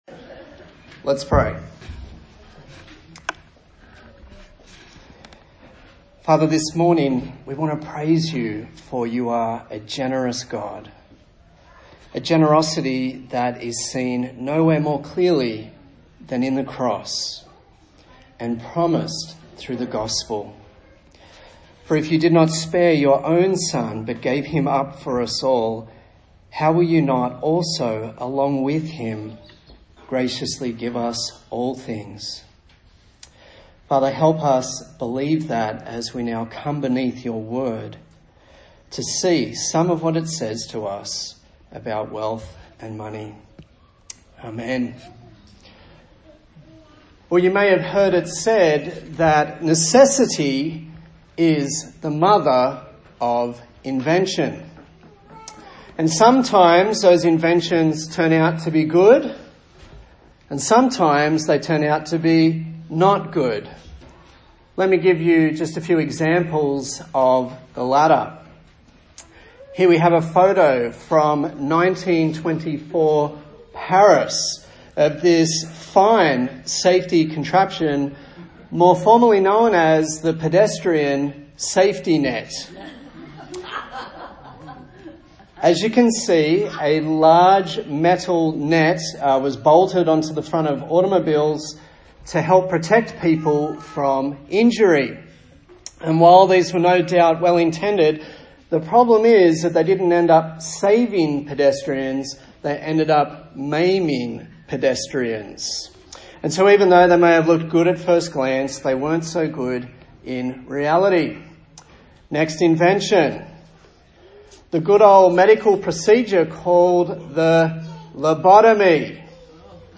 A sermon in the series on the book of Ecclesiastes